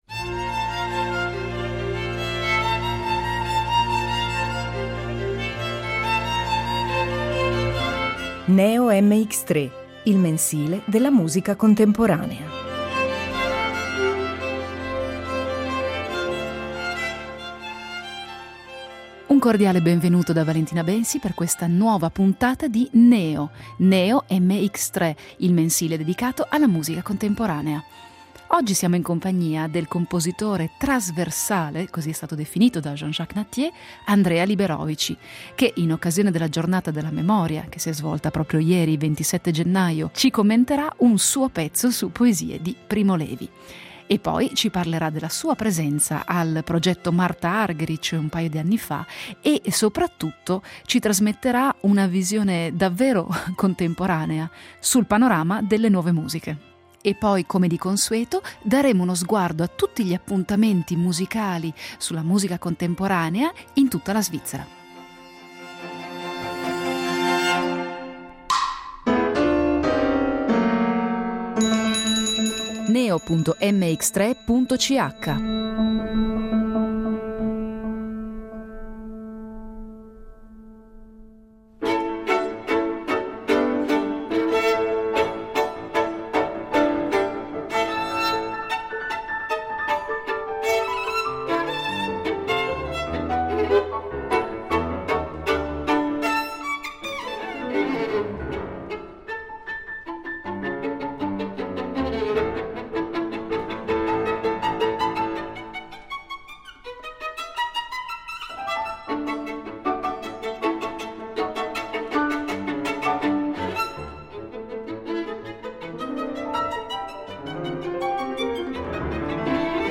Al termine della puntata ci sarà come sempre una breve agenda per ricordare gli appuntamenti dal vivo con la musica contemporanea in Svizzera.